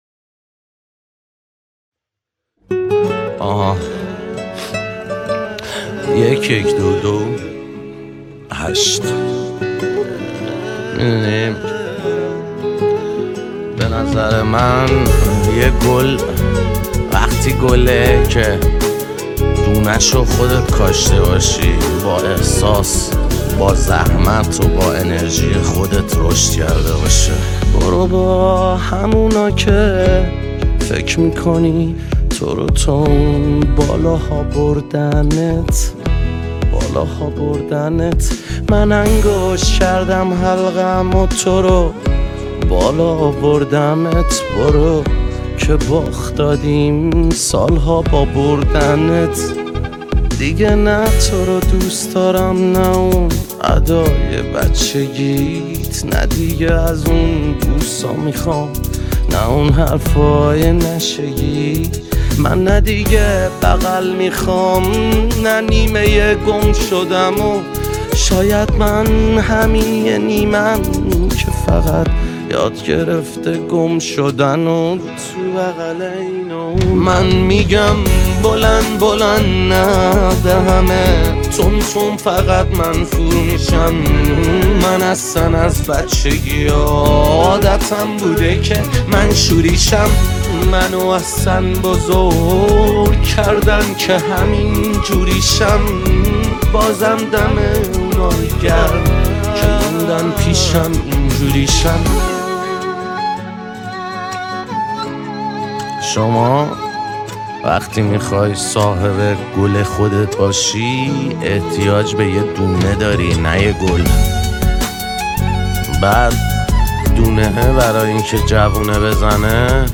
تک آهنگ
آراَندبی